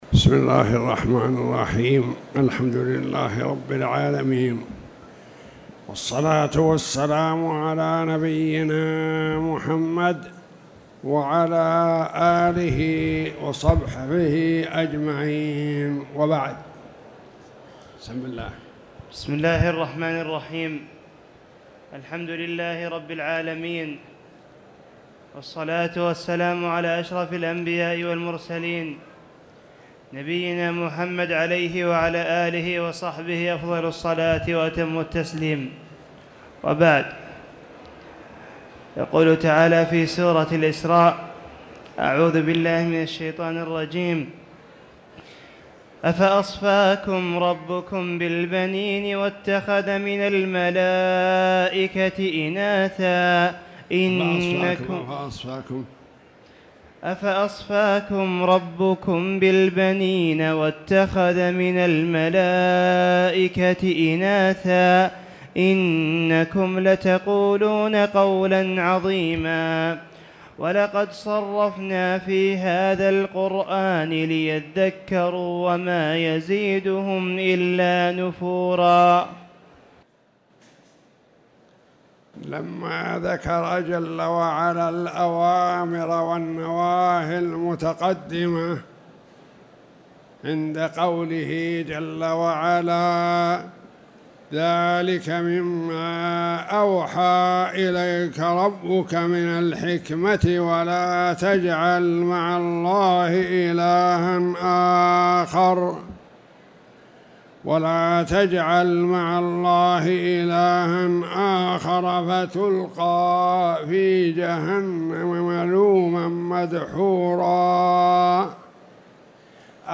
تاريخ النشر ٤ شعبان ١٤٣٧ هـ المكان: المسجد الحرام الشيخ